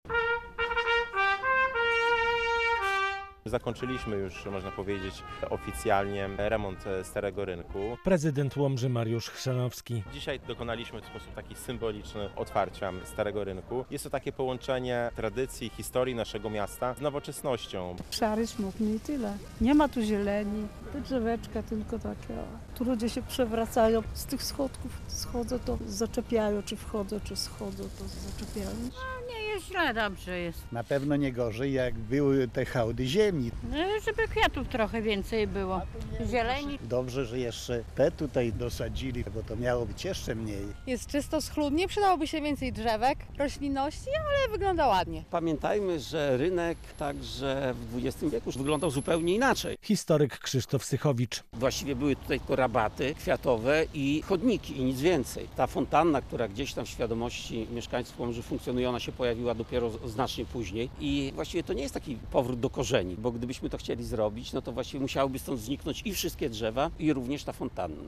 Rynek na 605 urodziny - relacja